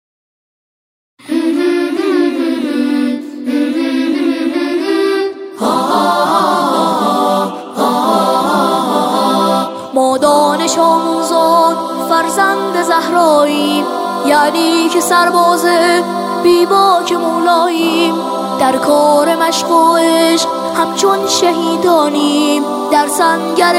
در این قطعه، شعری با موضوع دفاع مقدس همخوانی می‌شود.